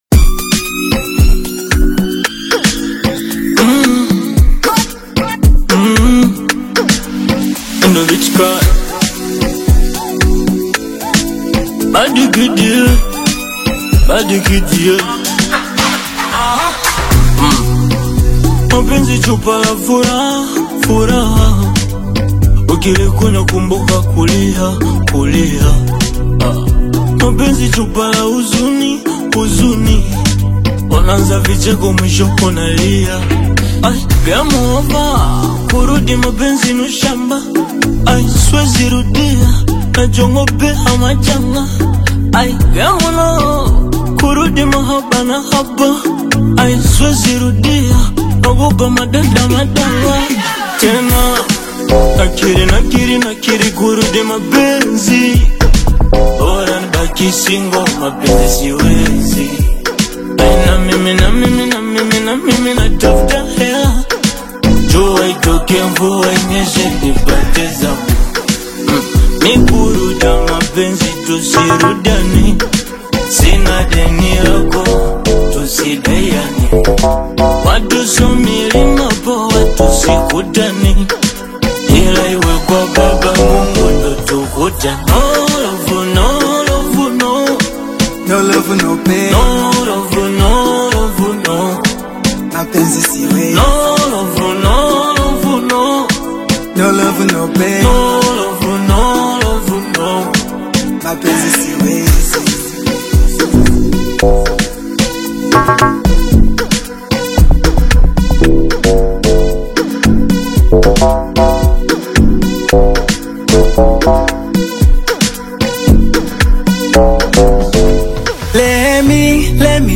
Bongo Flava/Afro-Pop collaboration
smooth Tanzanian style